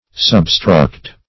Search Result for " substruct" : The Collaborative International Dictionary of English v.0.48: Substruct \Sub*struct"\, v. t. [See Substruction .] To build beneath something; to lay as the foundation.